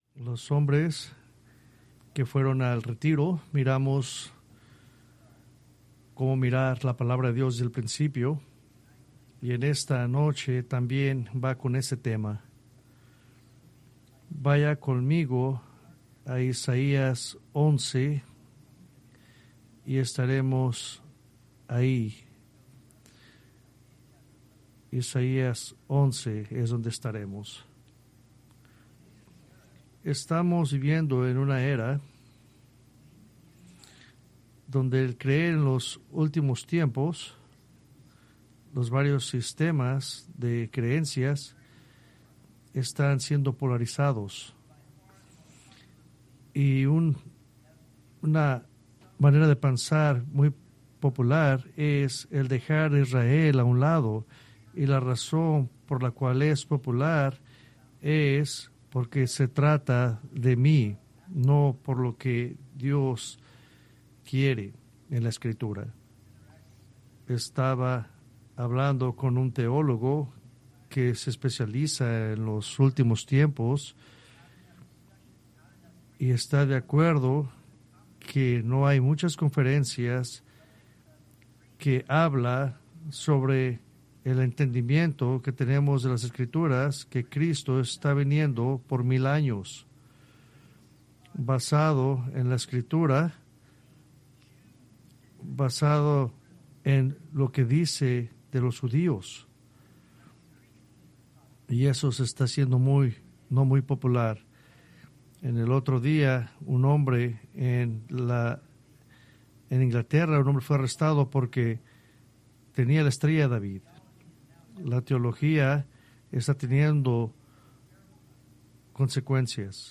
Preached October 19, 2025 from Escrituras seleccionadas